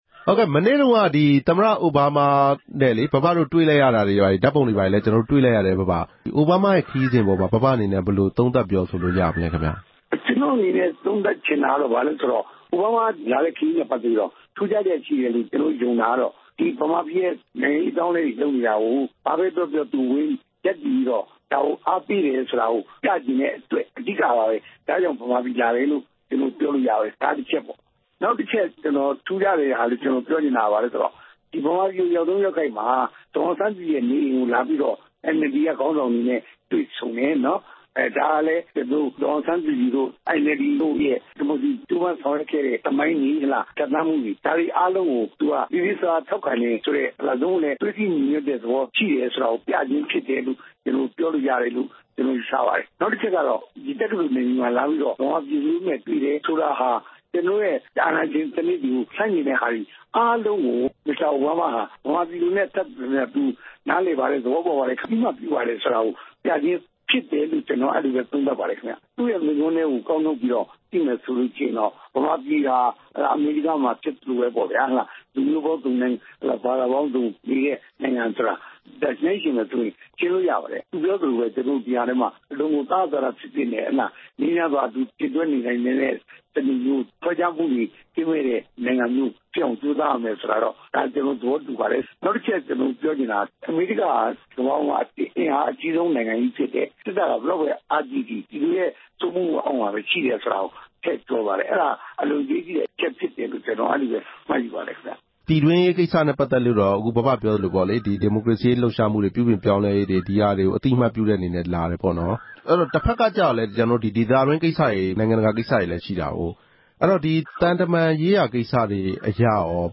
သတင်းစာဆရာကြီး ဦးဝင်းတင်ကို အိုဘားမား မိန့်ခွန်းအကြောင်း မေးမြန်းချက်